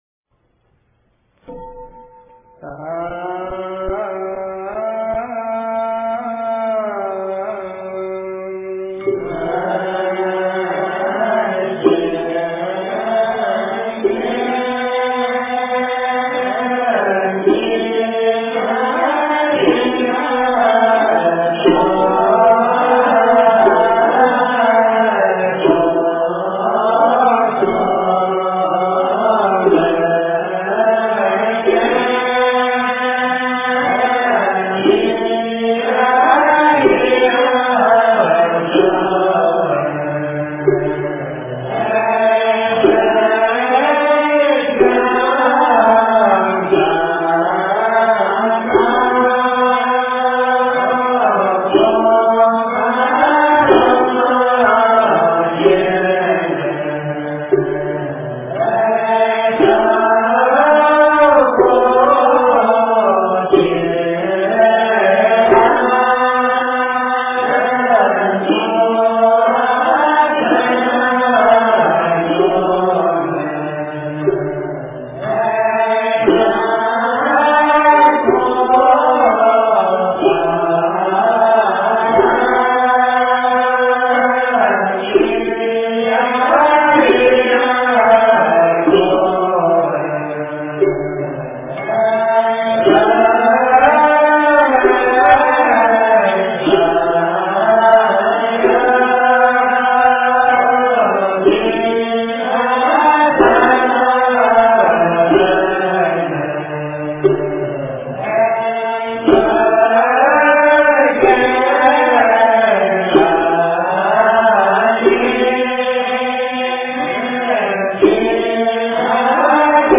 赞佛偈绕佛--上江城梵呗 经忏 赞佛偈绕佛--上江城梵呗 点我： 标签: 佛音 经忏 佛教音乐 返回列表 上一篇： 早课--万佛圣城梵呗 下一篇： 四大祝延--普陀山梵唄 相关文章 南无妙吉祥菩萨--中国佛学院法师 南无妙吉祥菩萨--中国佛学院法师...